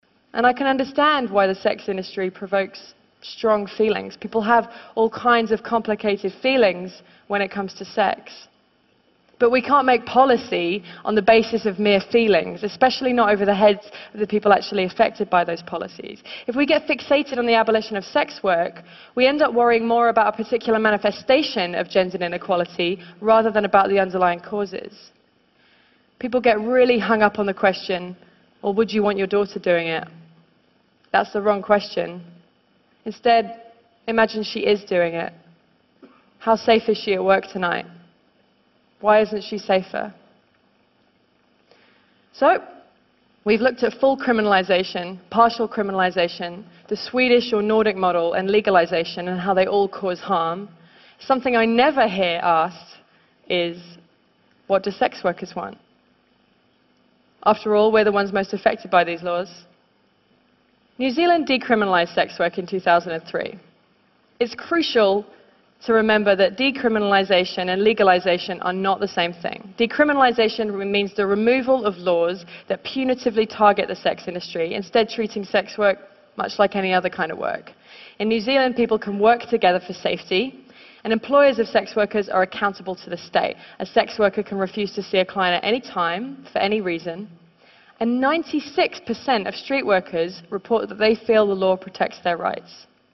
TED演讲:一位性工作者的诉求(10) 听力文件下载—在线英语听力室